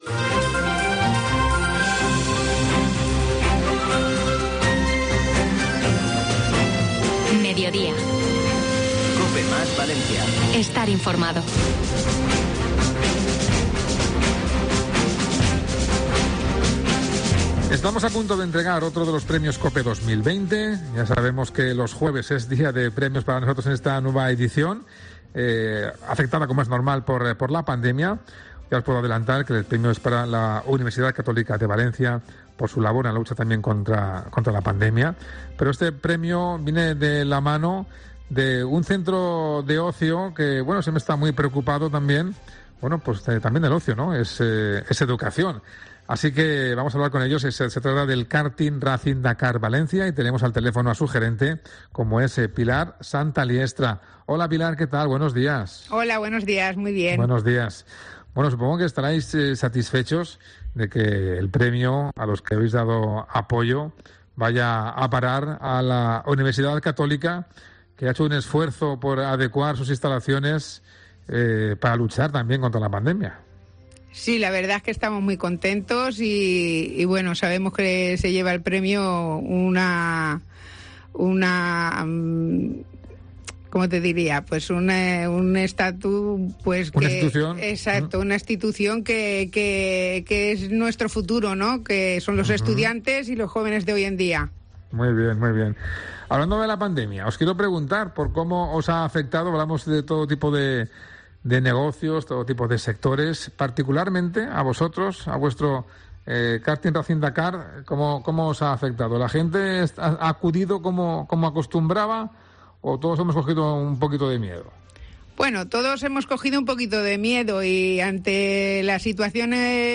Mediodía COPE MÁS Valencia | entrevista premio Sanidad 2020